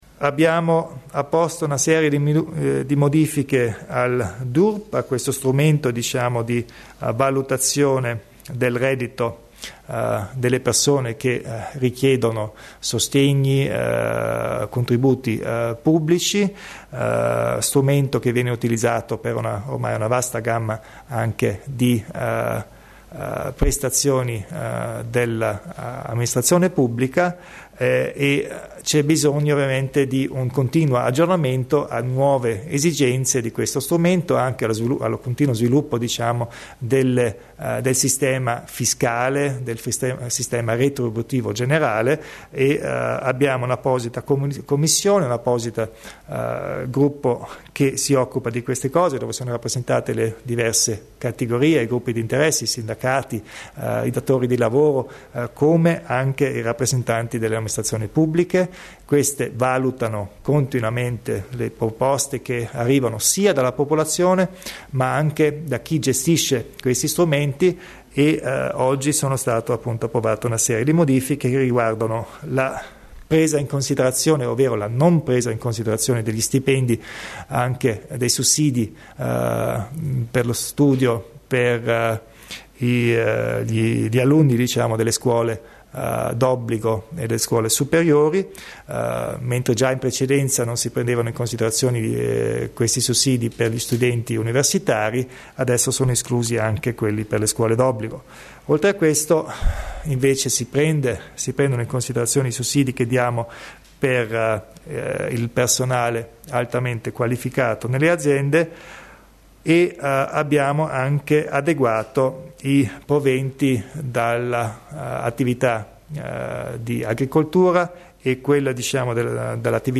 Il Presidente Kompatscher illustra le modifiche riguardanti il DURP